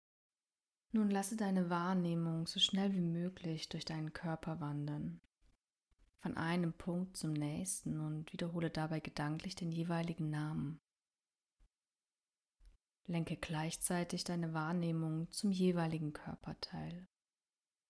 Yoga Nidra ist eine Methode der geführten Meditation zur Tiefenentspannung, die im Liegen (Savasana) ausgeführt wird. Durch eine gedankliche Reise durch deinen Körper und Geist bist du während der gesamten Praxis wach, gelangst jedoch in einen Entspannungszustand der dem Schlaf ähnelt.
Yoga-Nidra_neu-Parkspaziergang-Hoerprobe.mp3